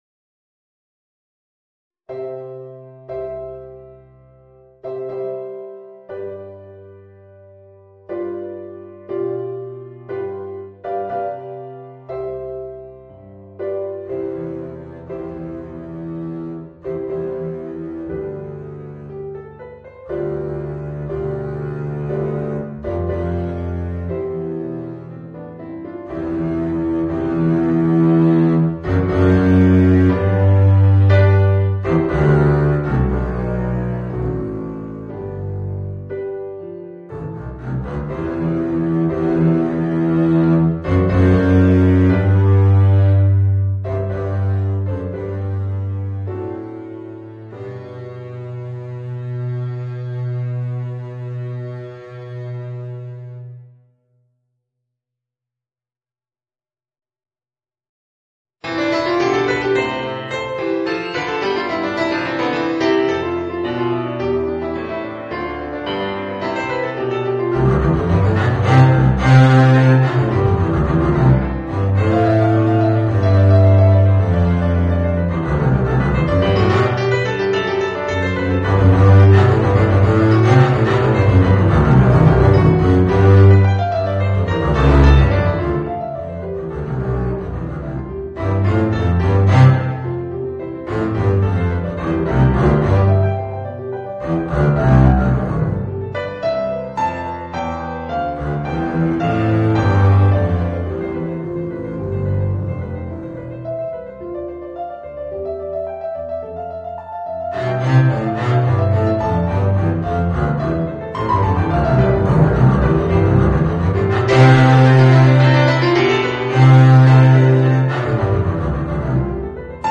Voicing: Contrabass and Organ